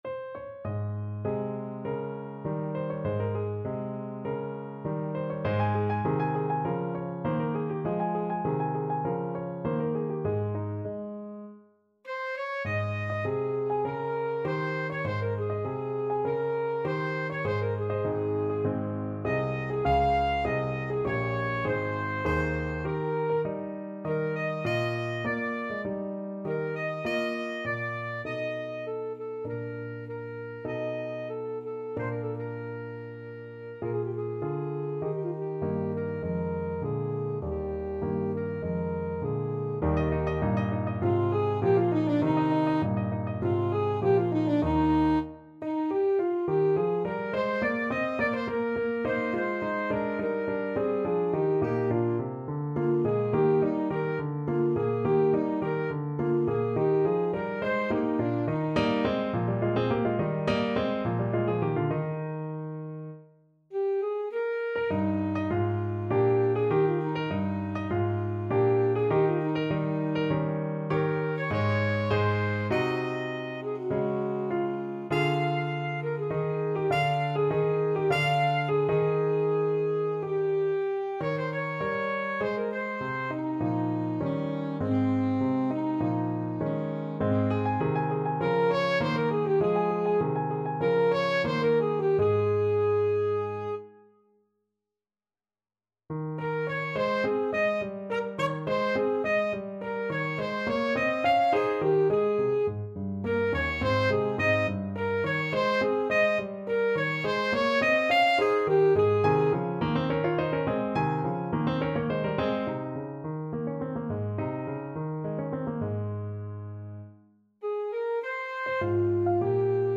Classical Pergolesi, Giovanni Battista A Serpina Penserete from La Serva padrona Alto Saxophone version
2/4 (View more 2/4 Music)
~ = 50 Larghetto
Classical (View more Classical Saxophone Music)